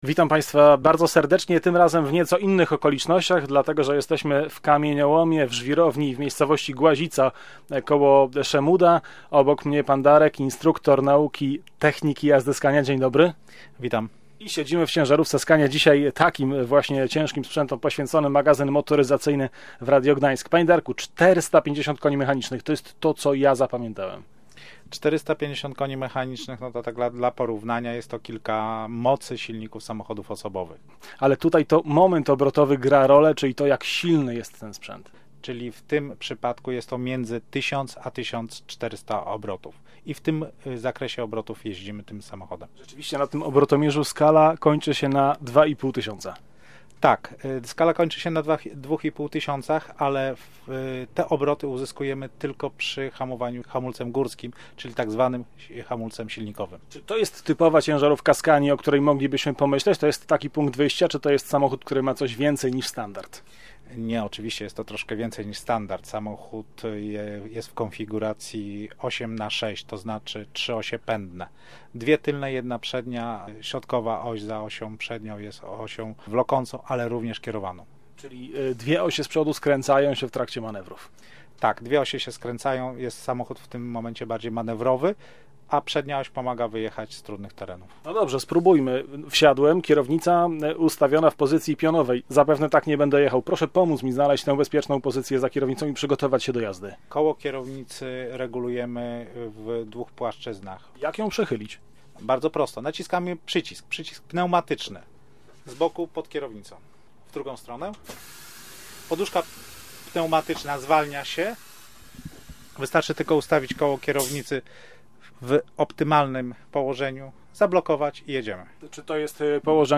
Na Pomorzu samochody specjalistyczne tej skandynawskiej marki oglądać i testować można było w żwirowni Głazica koło Szemuda.